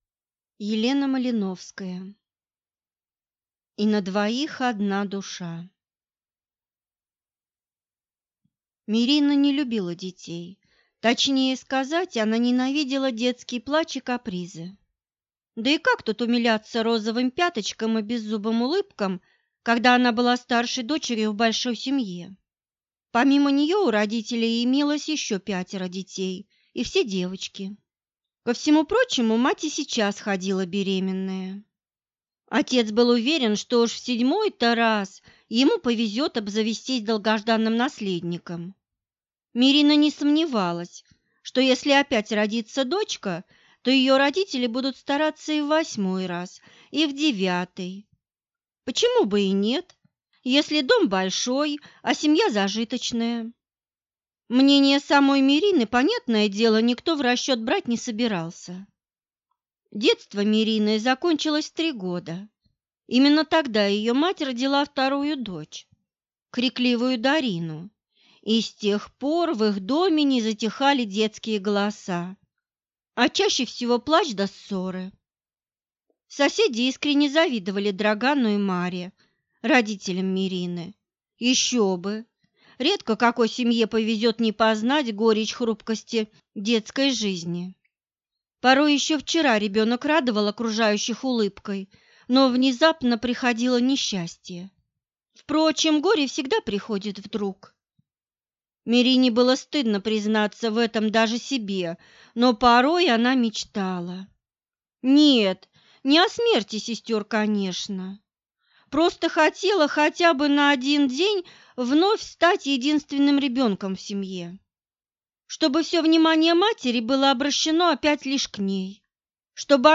Аудиокнига И на двоих одна душа | Библиотека аудиокниг